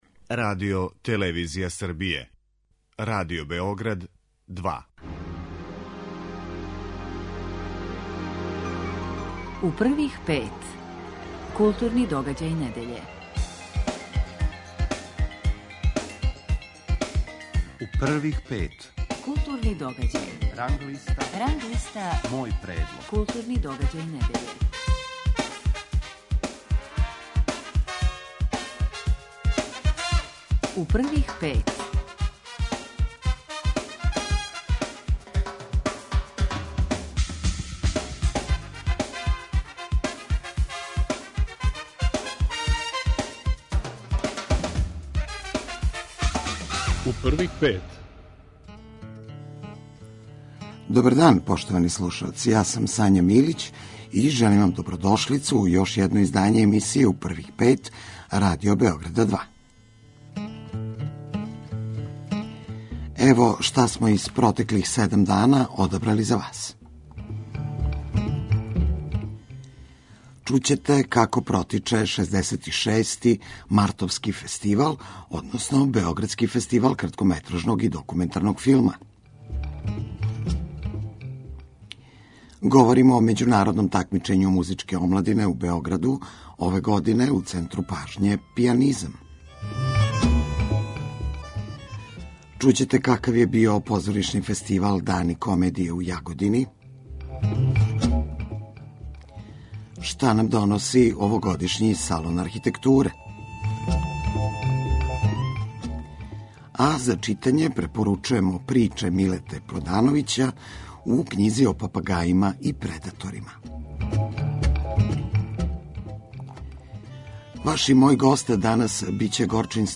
Гост емисије је Горчин Стојановић, редитељ, уметнички директор Југословенског драмског позоришта.